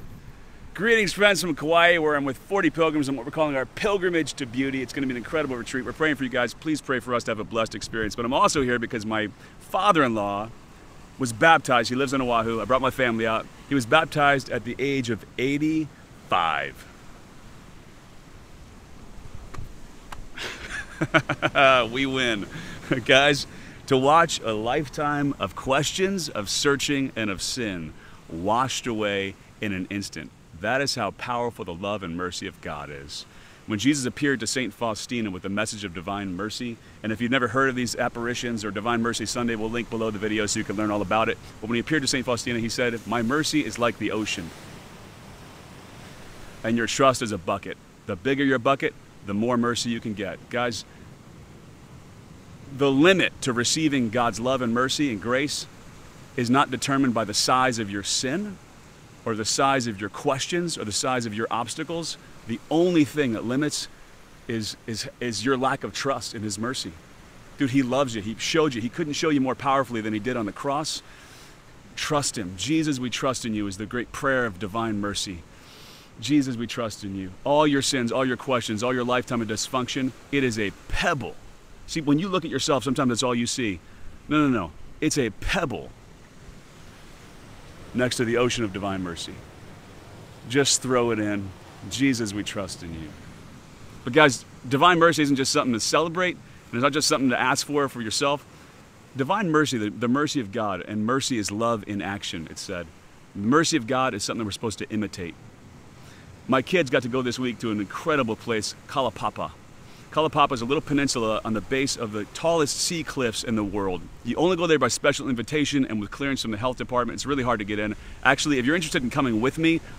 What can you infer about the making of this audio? I'm recording this from Hawaii, where my family is on a pilgrimage to beauty.